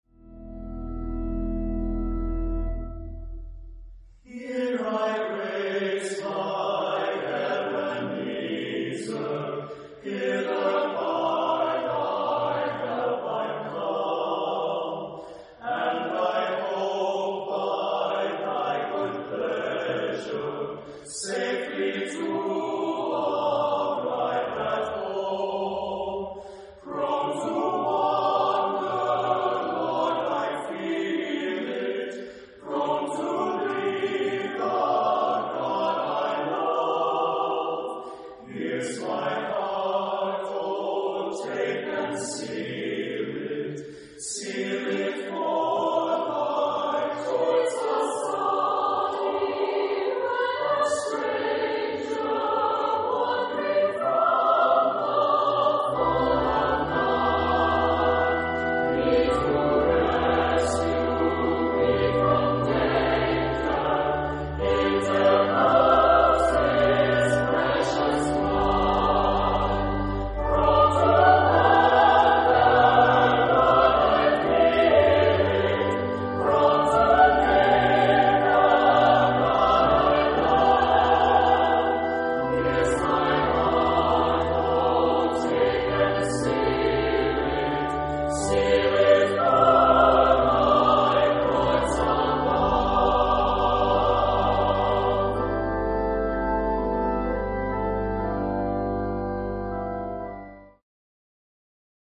SSAATTBB (8 voix mixtes) ; Partition complète.
Sacré. Arrangement en forme d'hymne.